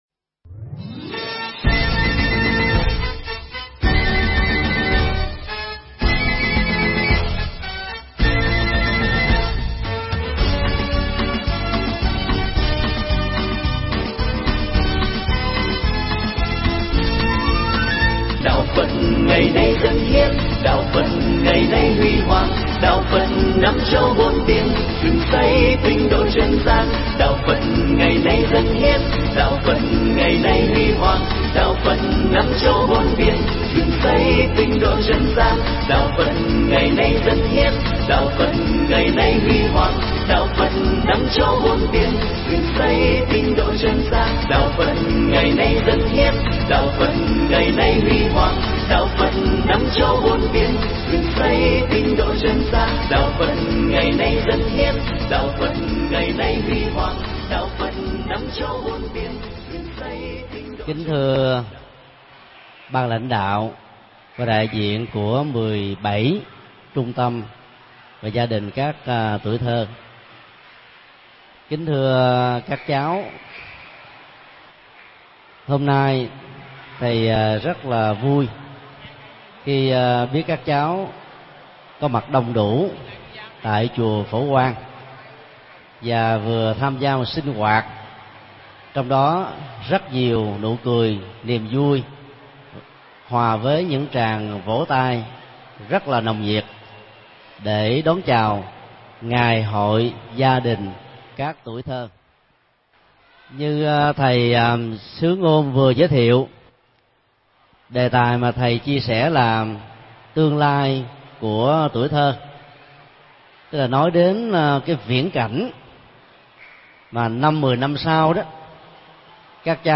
Pháp thoại
giảng trong ngày hội gia đình các tuổi thơ tại chùa Phổ Quang ngày 03 tháng 02 năm 2010